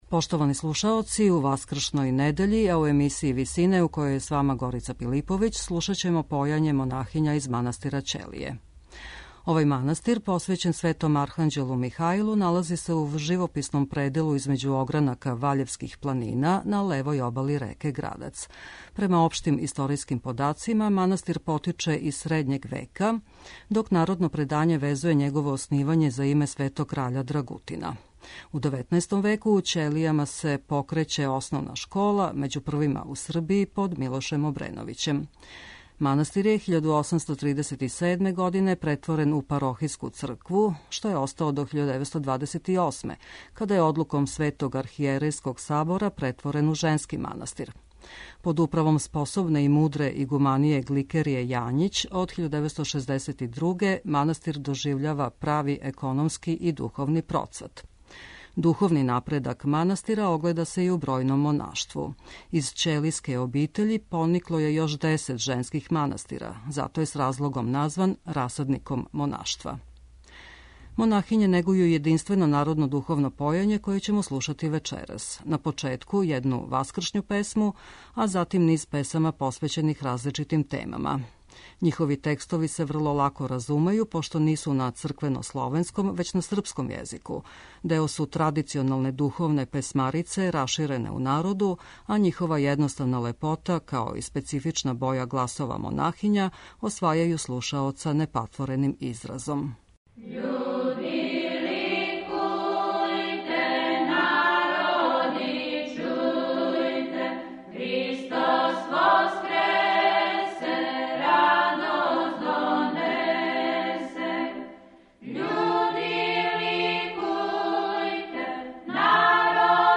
Појање монахиња из манастира Ћелије
Монахиње овог манастира, из чије је обитељи поникло још десет женских манастира и због чега је назван расадником монаштва, негују јединствено народно духовно појање. Вечерас ћемо прво чути једну ускршњу песму, а затим ние песама посвећених различитим религиозним темама.
Оне су део традиционалне духовне песмарице раширене у народу, а њихова једноставна лепота, као и специфична боја гласова монахиња, освајају слушаоца непатвореним изразом.